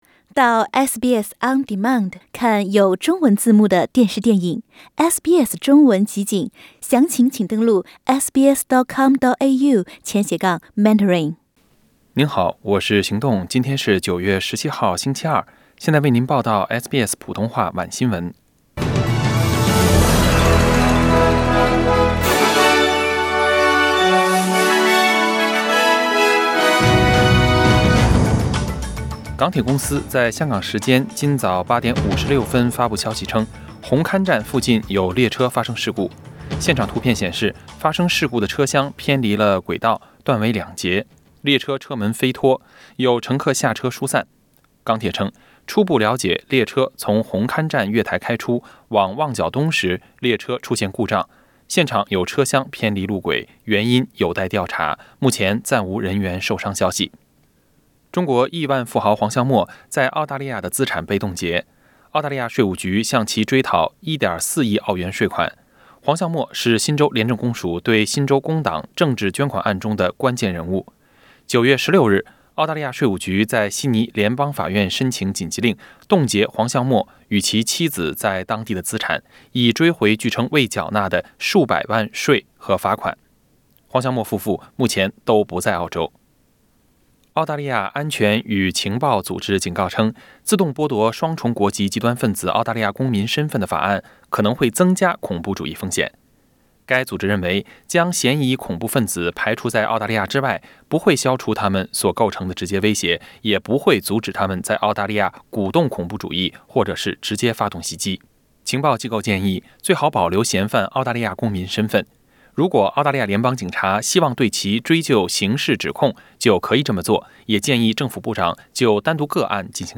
SBS晚新闻 （9月17日）